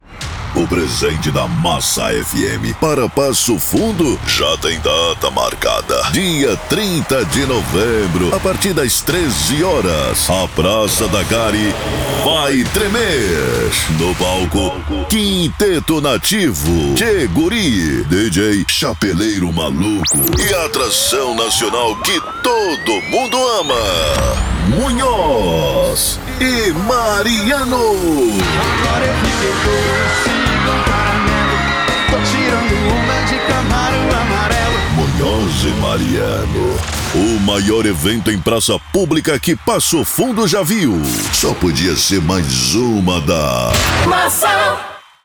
Spot - Show Massa Munhoz e Mariano 002: